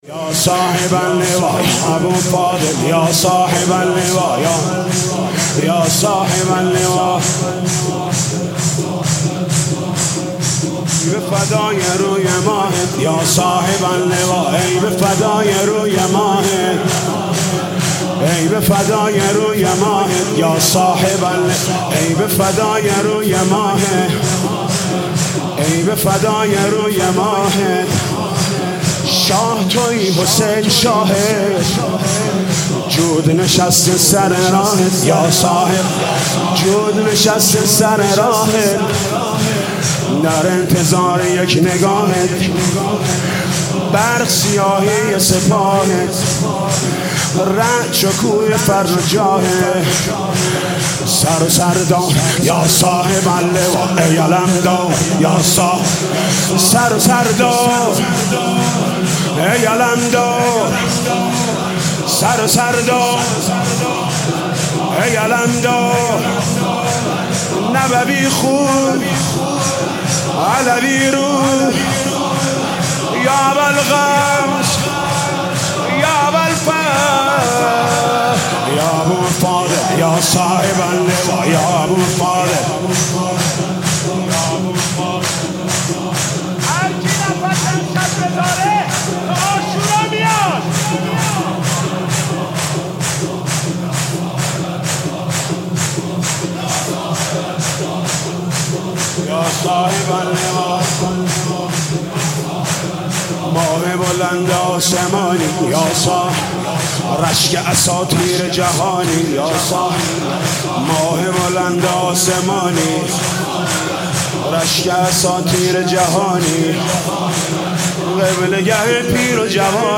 شور
نوحه محرم